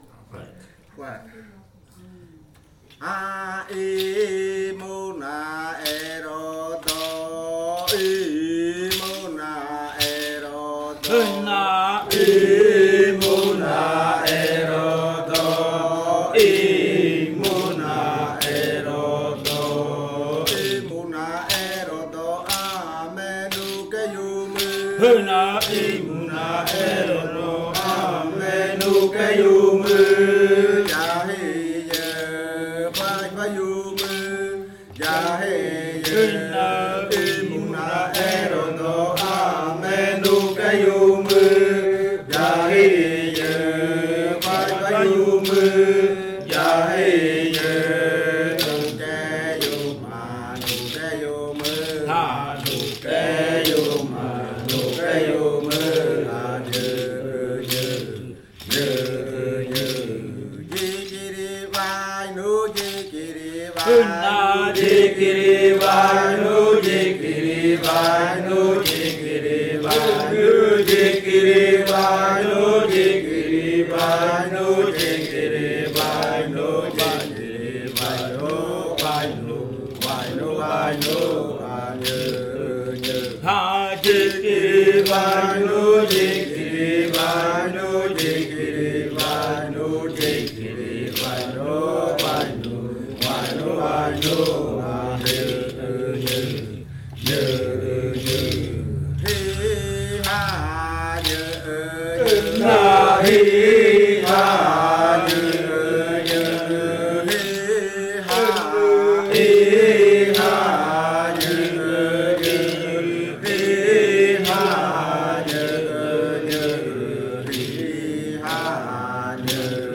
Canto de la variante jimokɨ
con el grupo de cantores sentado en Nokaido. Este canto hace parte de la colección de cantos del ritual yuakɨ murui-muina (ritual de frutas) del pueblo murui, colección que fue hecha por el Grupo de Danza Kaɨ Komuiya Uai con apoyo de la UNAL, sede Amazonia.
with the group of singers seated in Nokaido.